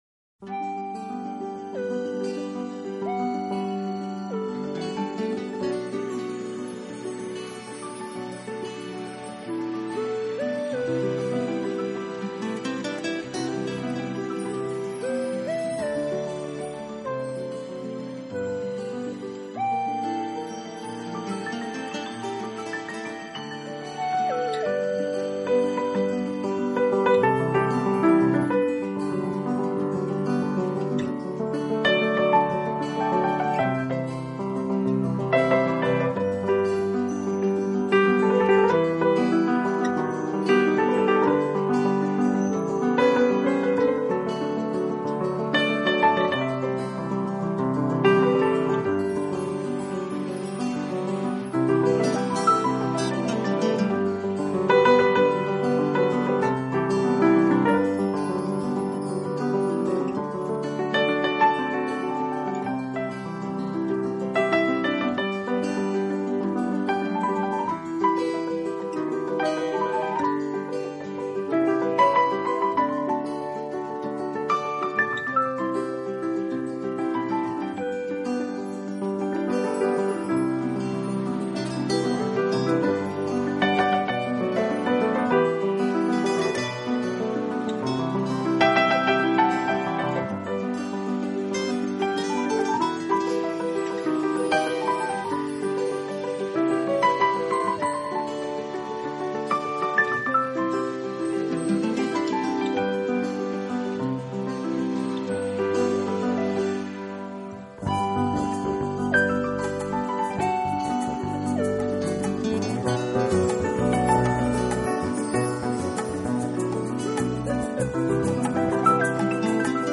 音乐类型：Instrumental - piano